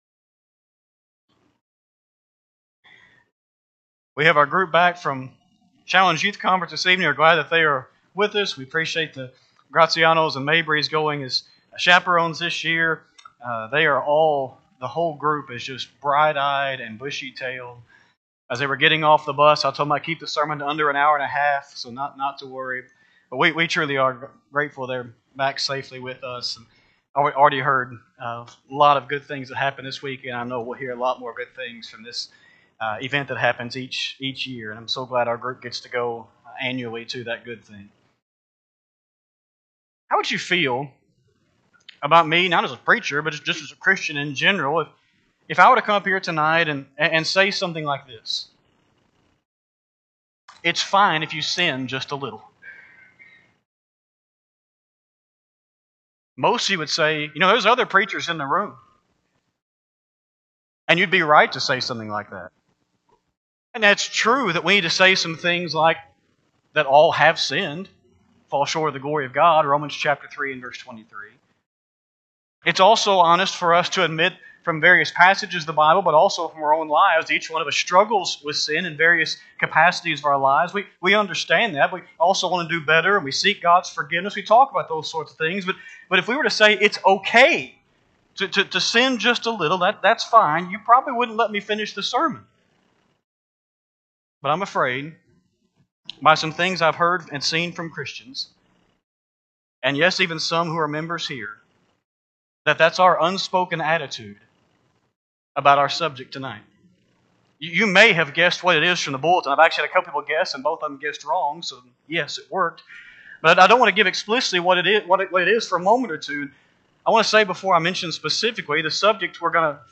Speaker 2/25/24 Sunday PM Sermon